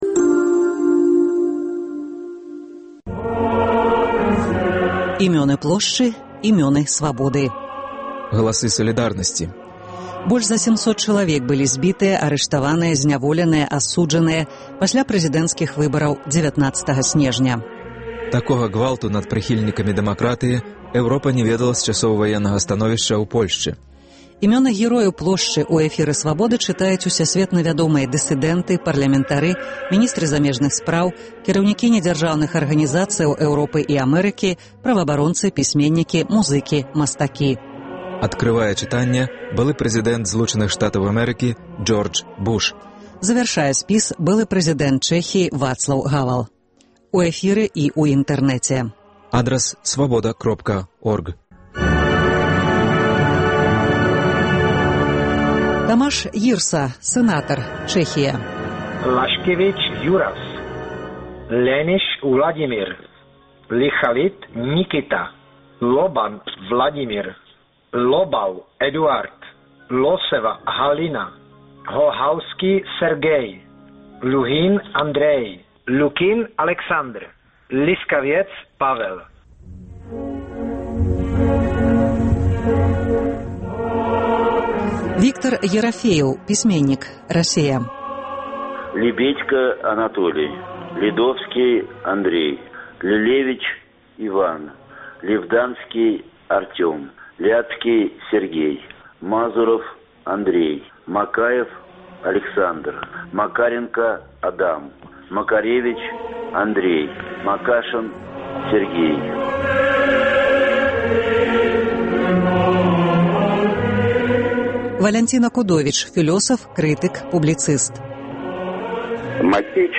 Імёны герояў Плошчы ў эфіры Свабоды чытаюць усясьветна вядомыя дысыдэнты, парлямэнтары, міністры замежных справаў, кіраўнікі недзяржаўных арганізацыяў Эўропы і Амэрыкі, праваабаронцы, пісьменьнікі, музыкі, мастакі. Адкрывае чытаньне былы прэзыдэнт Злучаных Штатаў Джордж Буш. Завяршае былы прэзыдэнт Чэхіі Вацлаў Гавэл.